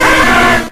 Cries
CHARMANDER.ogg